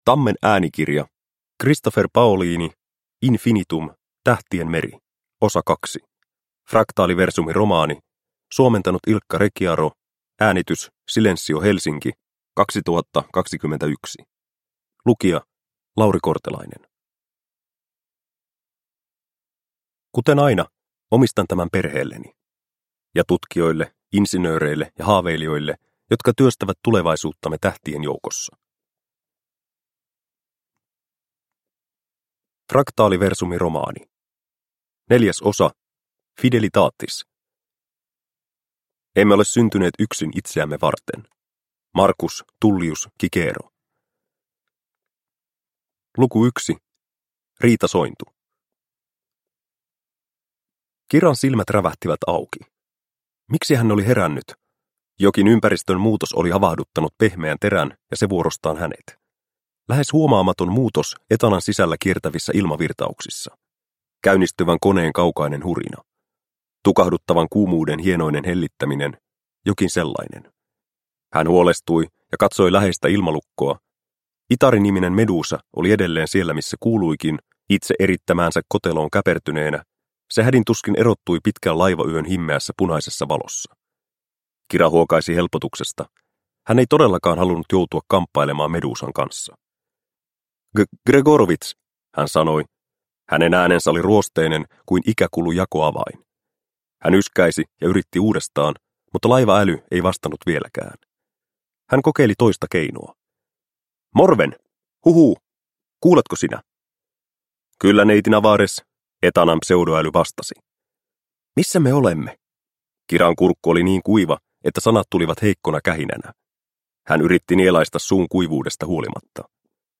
Infinitum. Tähtien meri. Osa 2 – Ljudbok – Laddas ner